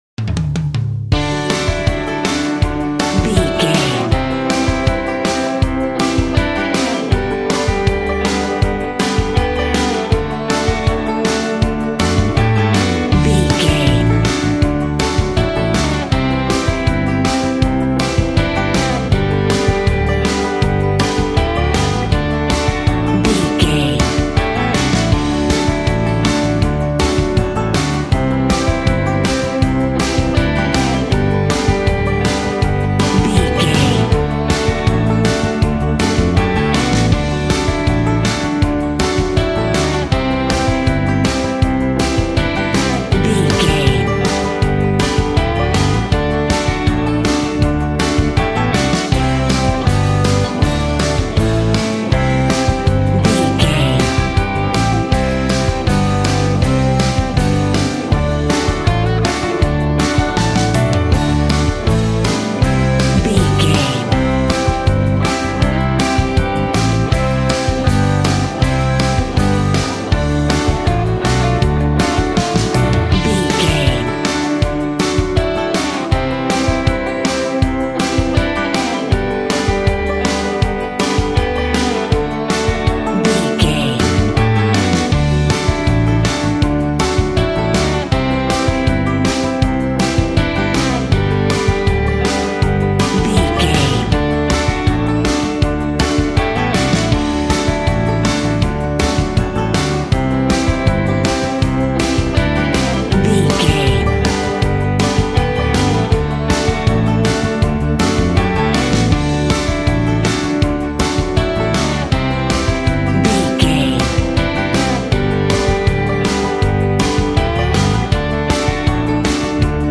Ionian/Major
D
cool
uplifting
bass guitar
electric guitar
drums
cheerful/happy